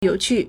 yǒuqù
you3qu4.mp3